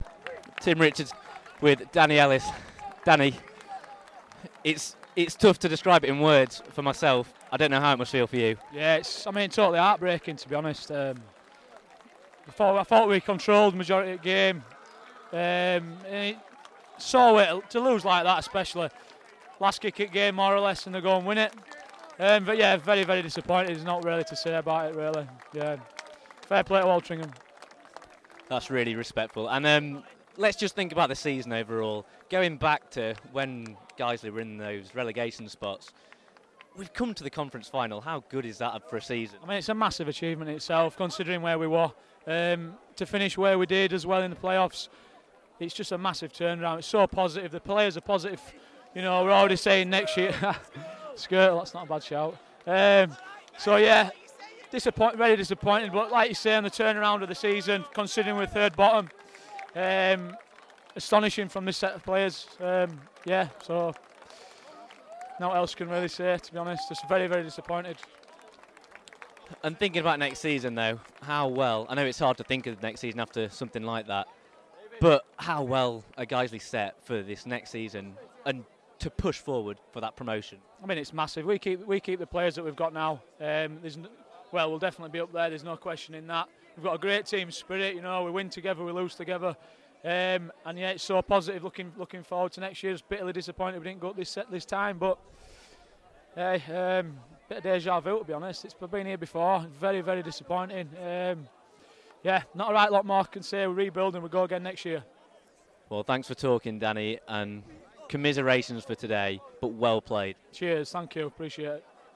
on the pitch after the game